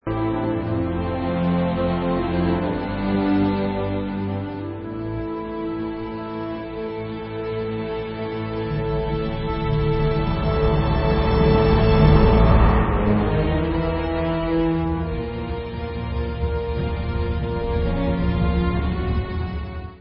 ve studiu Abbey Road